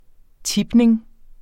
Udtale [ ˈtibneŋ ]